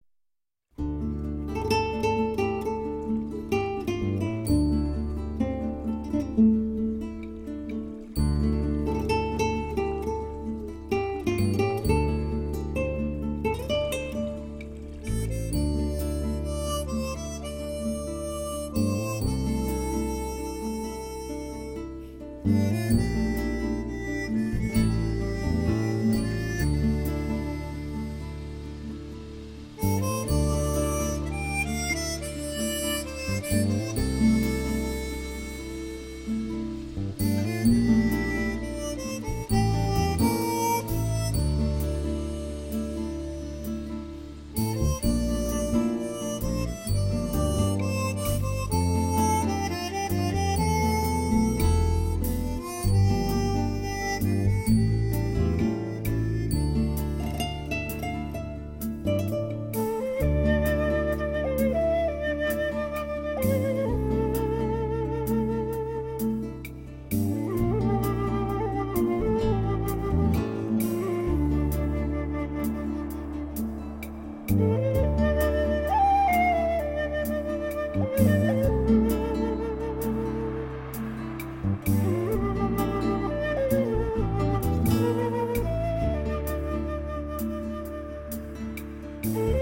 居然有小桥流水缓缓而过的声音穿插其中，